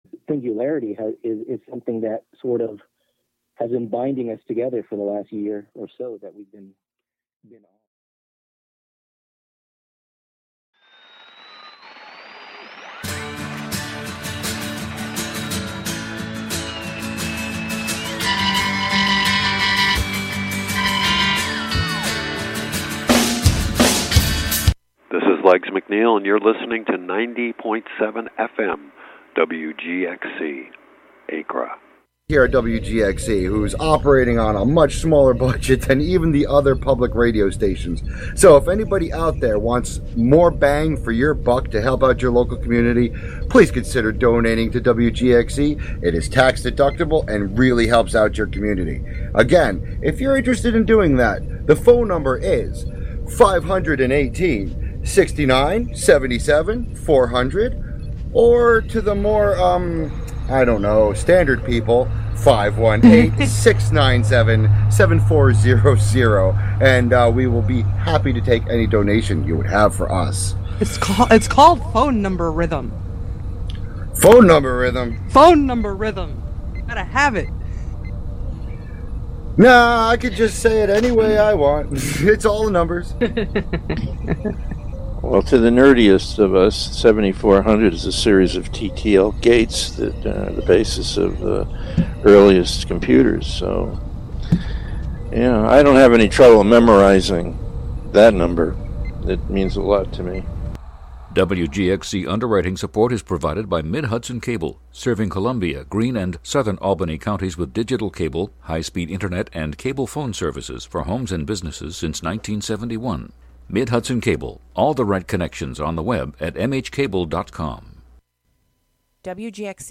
If you liked Car Talk , two wise guys answering questions about common automotive problems, you will love Tək Təlk , a weekly show by two wise guys answering questions about common tech glitches with computers, mobile devices, apps, and the Web.